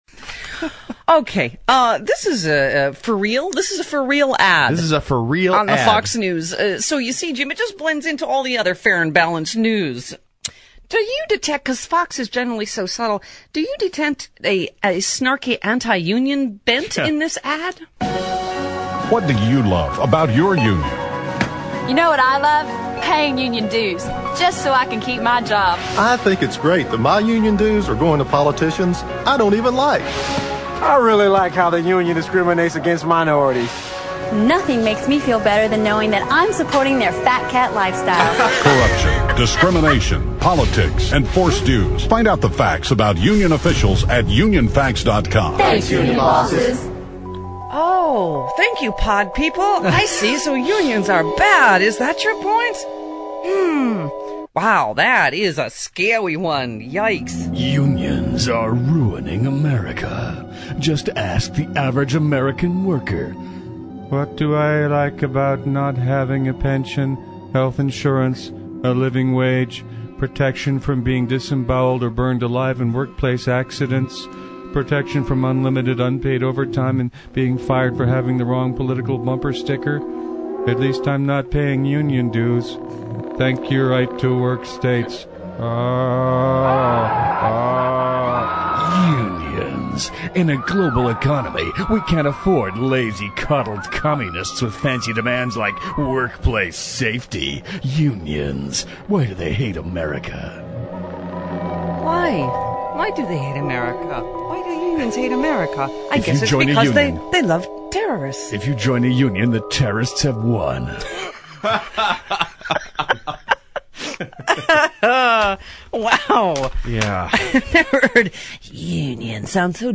ad run on Fox News...
UnionAdOnFox.mp3